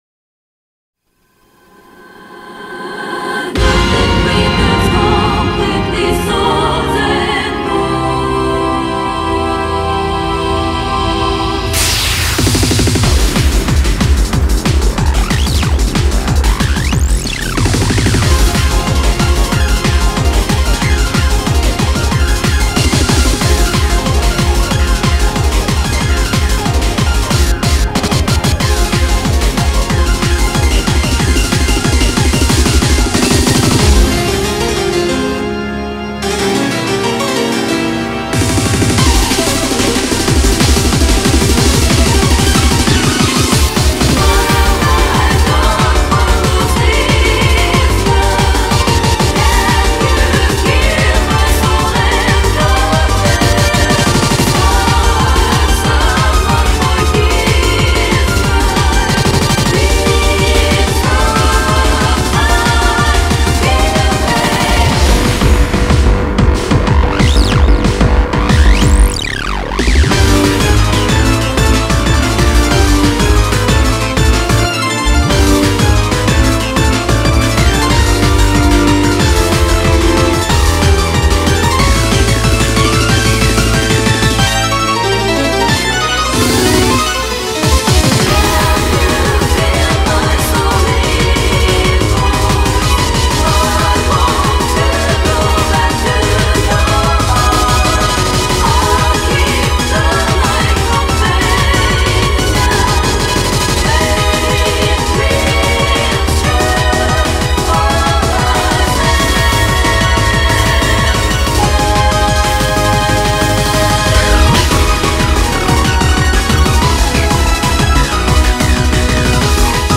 BPM46-185
Audio QualityPerfect (High Quality)
Comentarios[MEDIEVAL CHOIR]